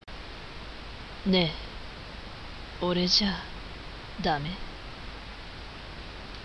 セリフ
1番目の台詞は、好きな子に彼氏がいても、お色気でアタックする感じです。